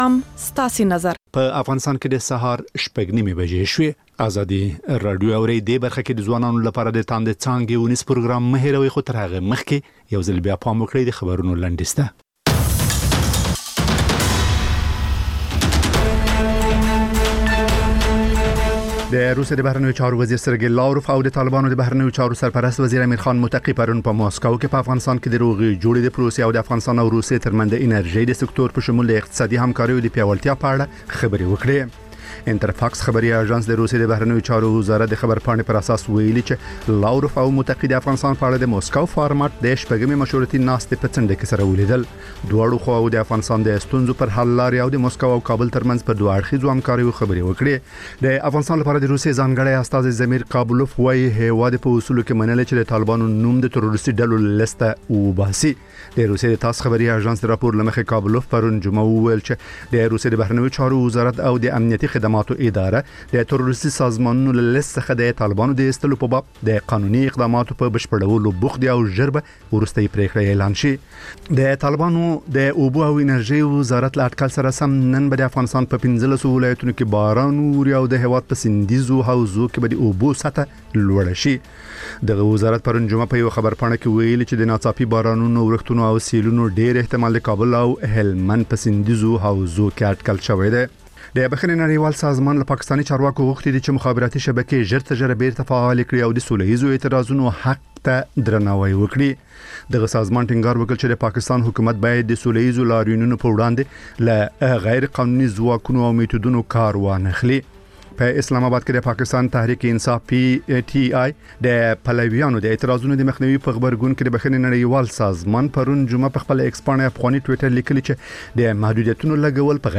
لنډ خبرونه - تاندې څانګې (تکرار)